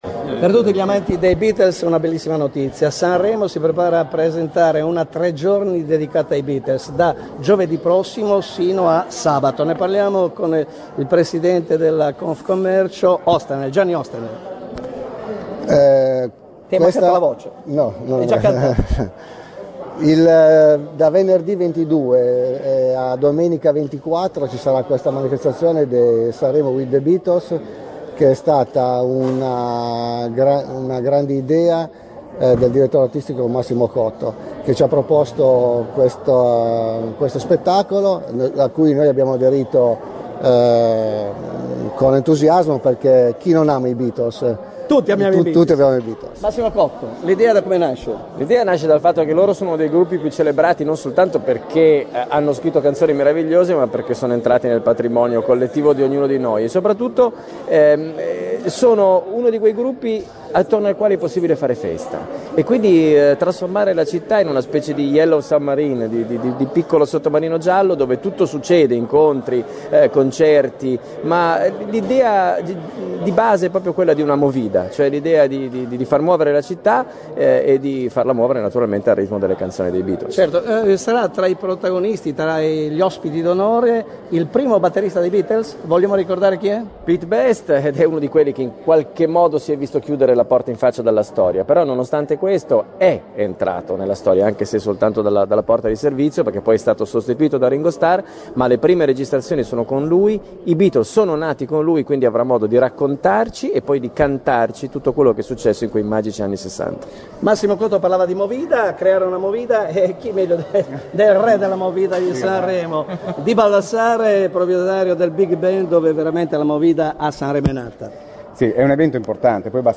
Cliccando sotto la gallery le dichiarazioni raccolte alla presentazione
Interviste Beatles 15Giu2012 (1.6 MB)